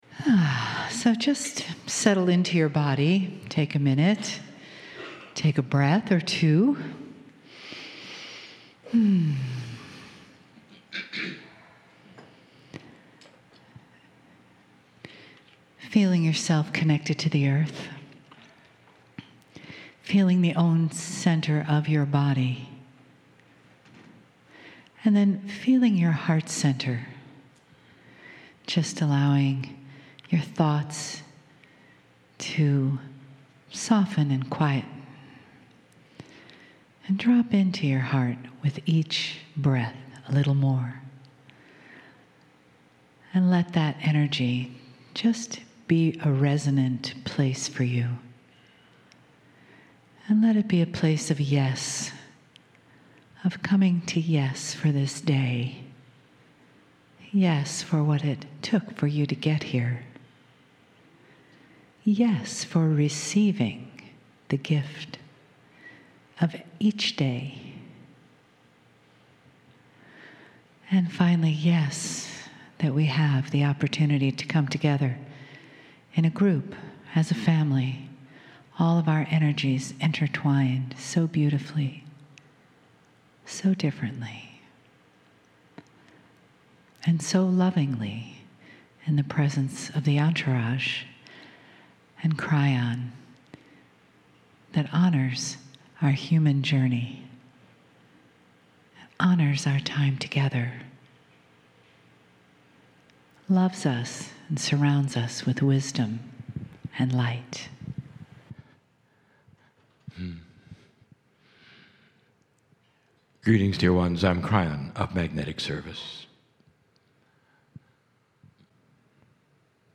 Regina, SK, Canada Sunday May 24, 2015
"Mini Channelling"